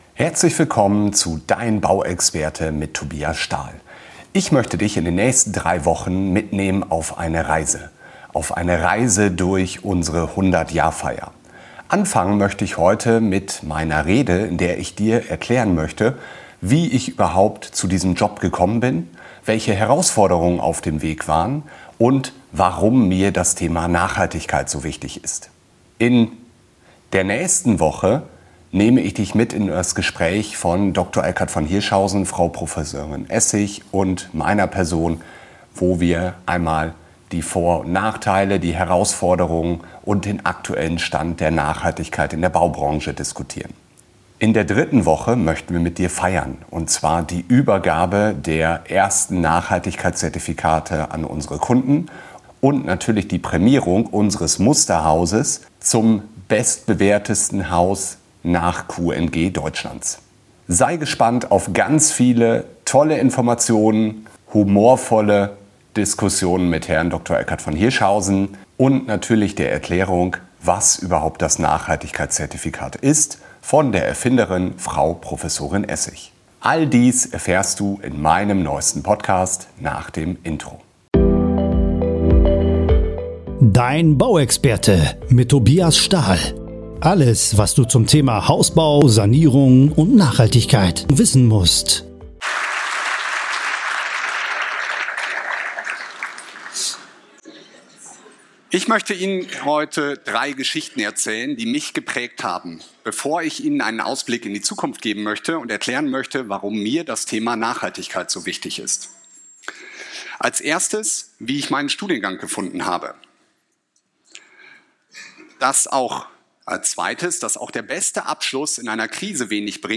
Jubiläumsfeier - Rede